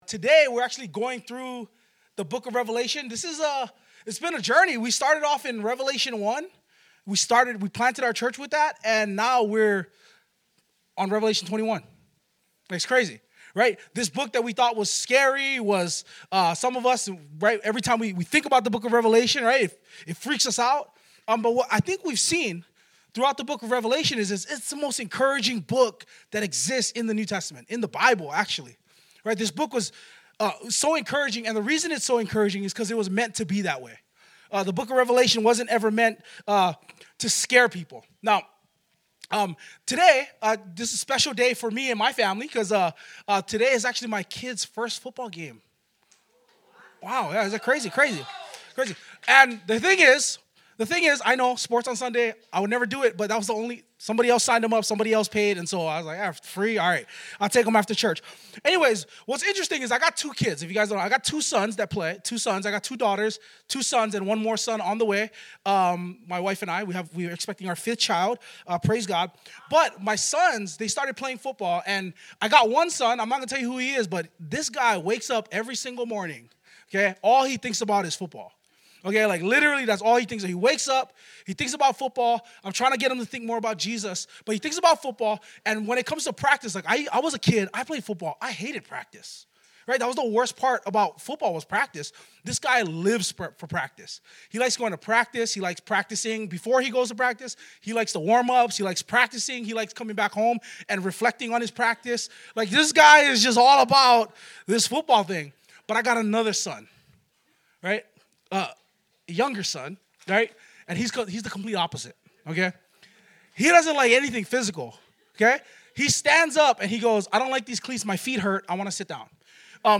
Triumph is Forever | Harbor Church Waipahu